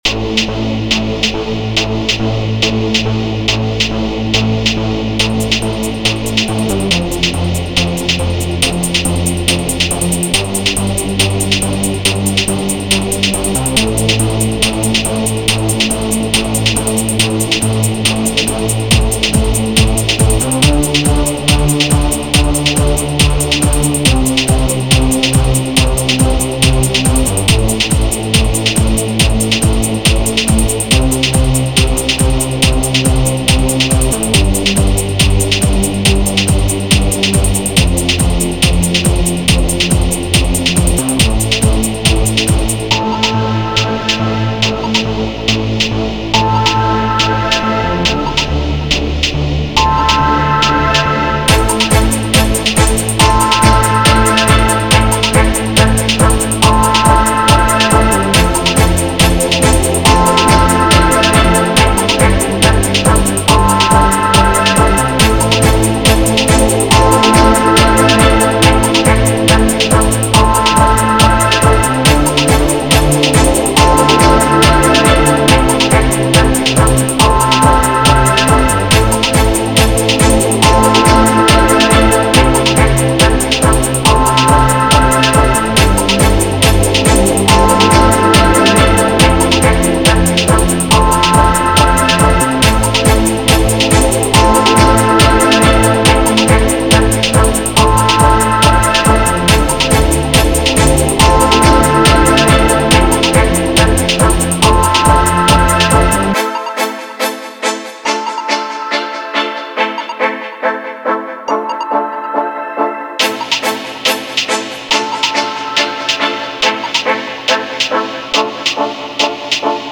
Lyric_Show_05_Working factory.mp3